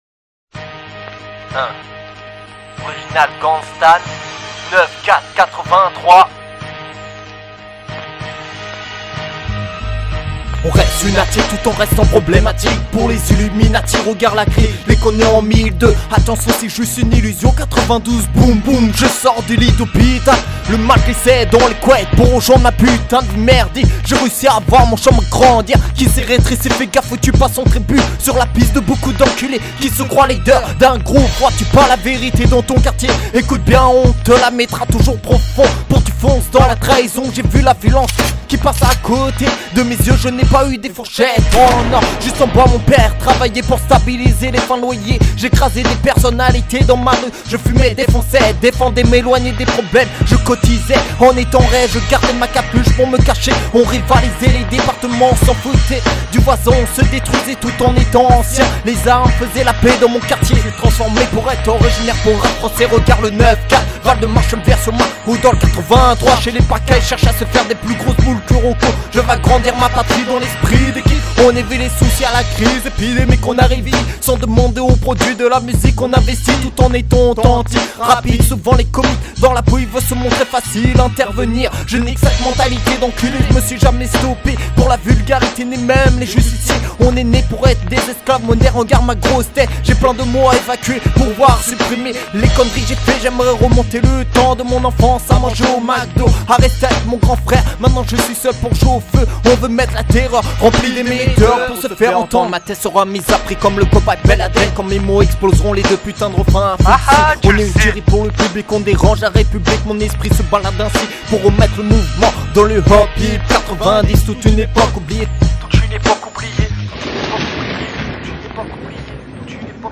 RAP ORIGINAL GANGSTA